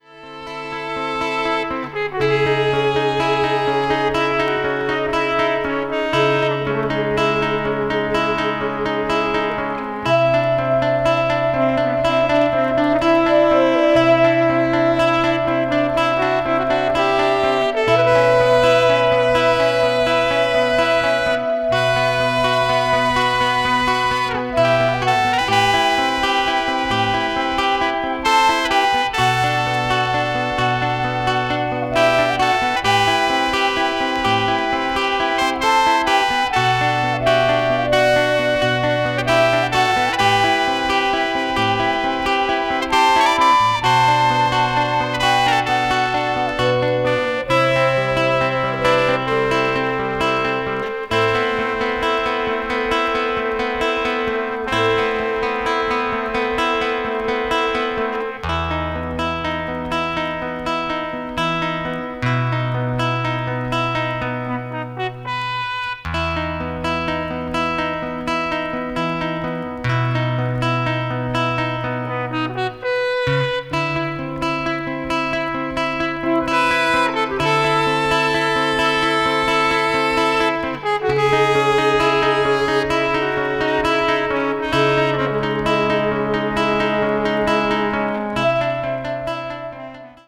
一瞬いなたく聞こえるかもしれませんが、聴いているうちにその世界に引き込まれていってしまう不思議な音楽です。